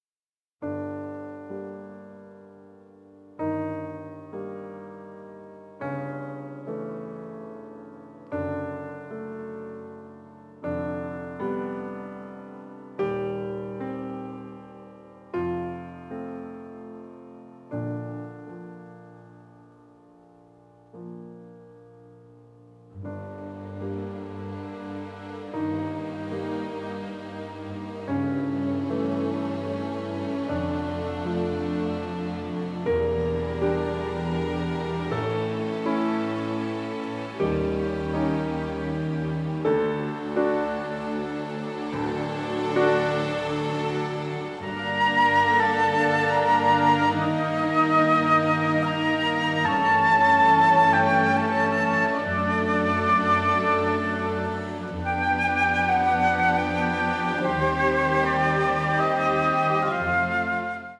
beautiful, nostalgic
radiantly passionate score